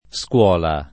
SkU0la] s. f. — pop. o poet. scola [Sk0la]: Scola d’errori et templo d’eresia [Sk0la d err1ri e tt$mplo d ere@&a] (Petrarca); anche uno sproposito gli serve di scola [